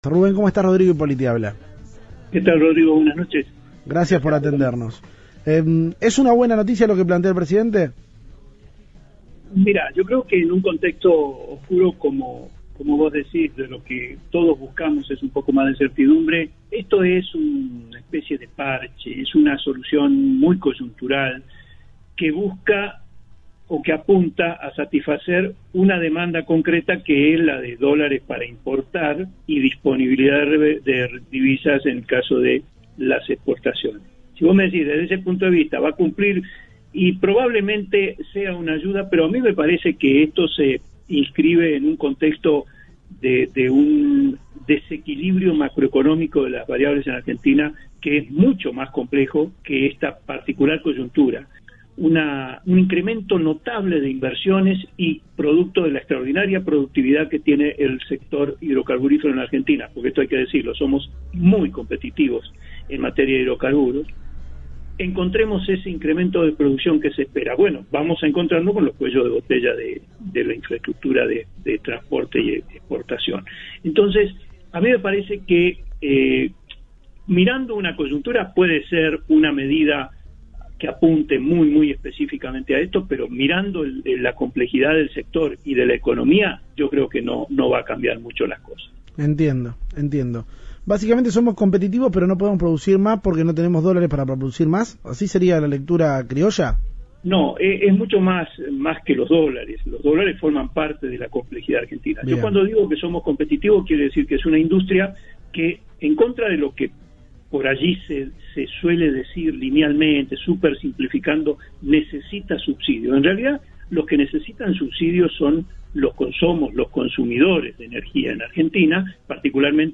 Un ingeniero especialista en el complejo hidrocarburífero analizó en Cadena 3 Rosario la medida de acceso a divisas para empresas, pidió planificación y valoró la “competitividad” del sector.
Audio. Un experto en el sector petrolero habló tras el alivio en el cepo a empresas.